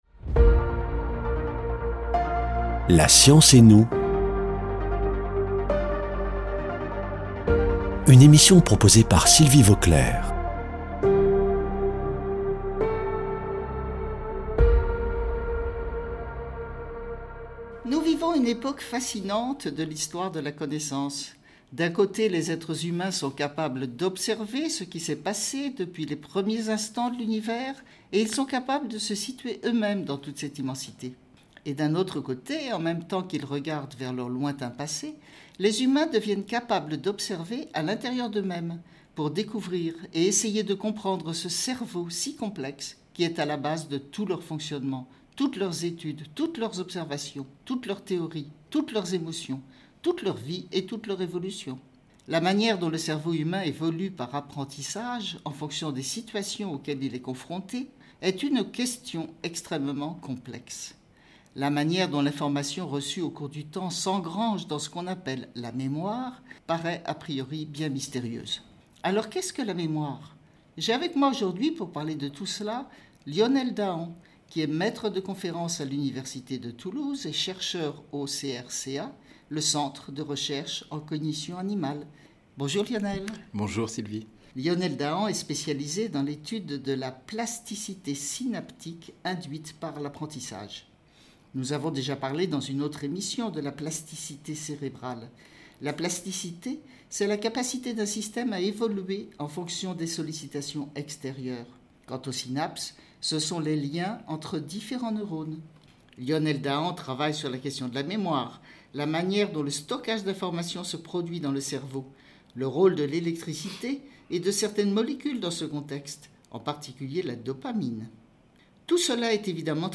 docteur en neurosciences, parle du fonctionnement cérébral et de la mémoire (1/3)